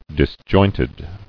[dis·joint·ed]